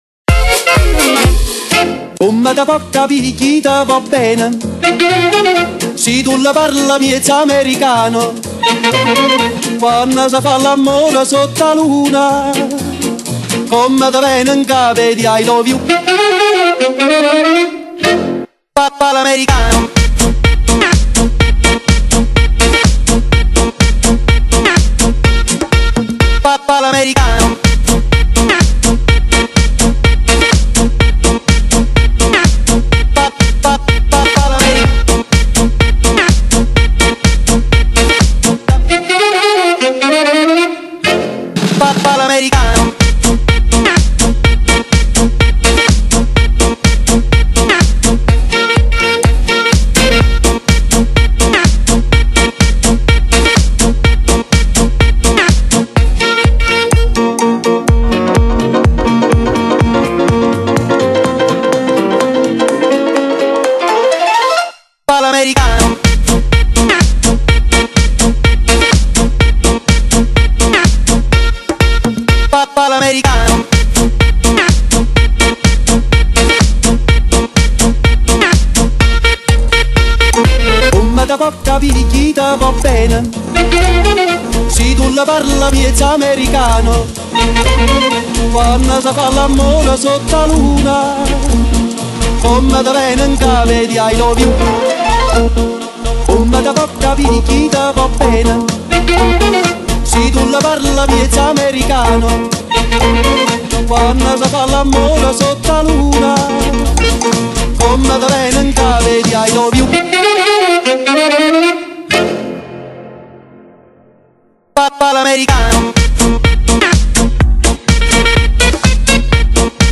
177 MB Dance, Club
一张当红的电子舞曲集锦。